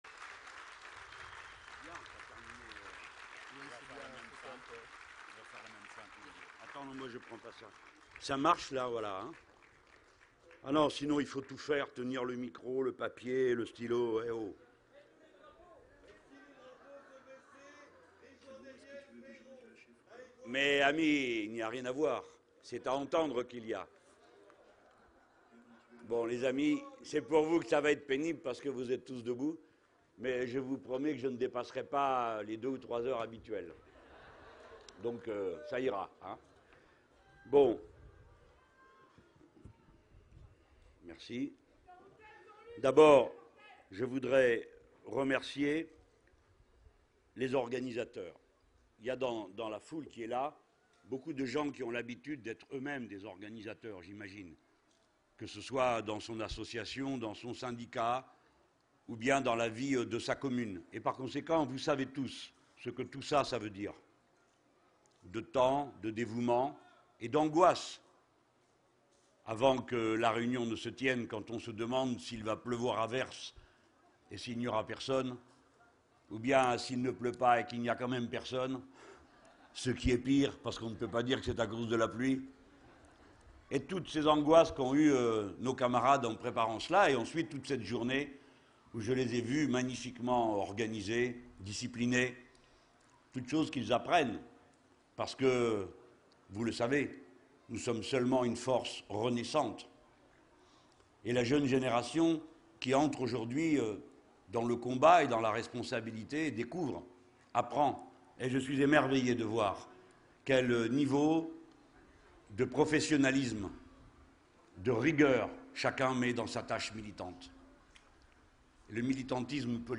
Discours de de Jean-Luc Mélenchon à la fête du Front de Gauche le 15 juin 2013 à Besançon :